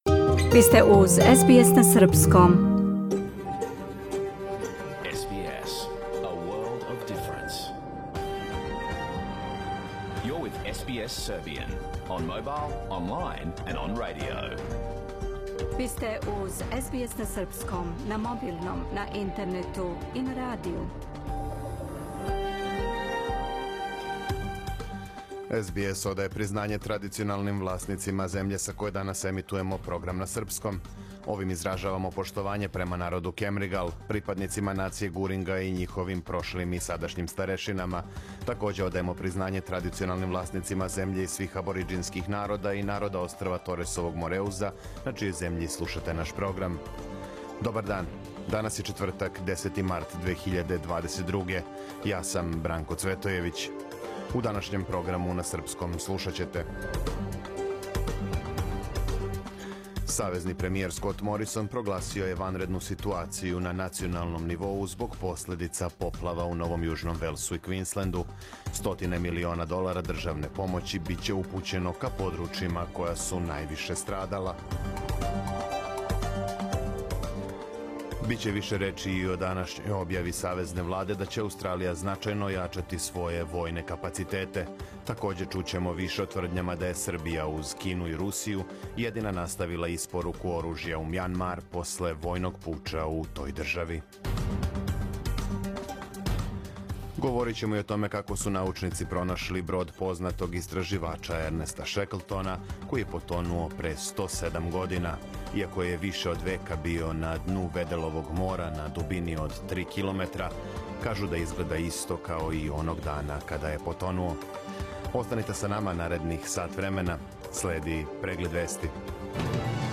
Програм емитован уживо 10. марта 2022. године
Ако сте пропустили нашу емисију, сада можете да је слушате у целини као подкаст, без реклама.